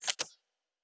Weapon 04 Reload 1.wav